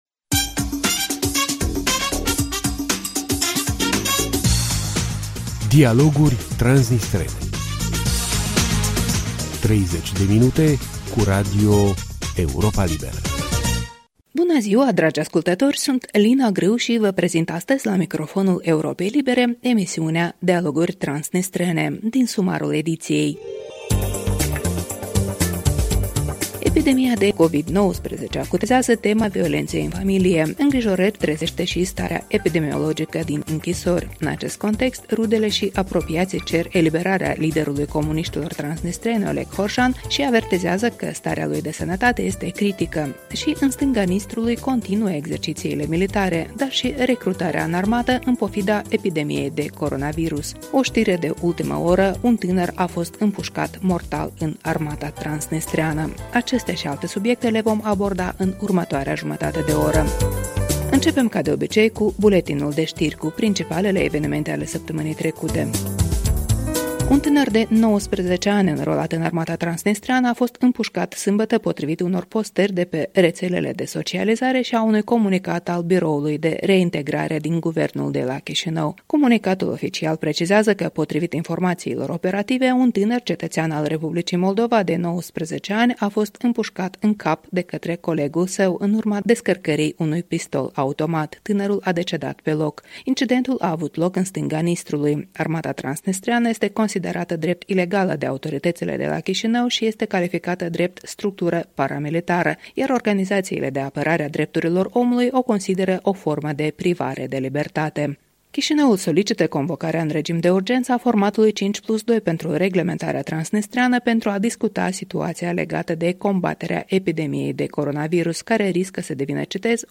O emisiune moderată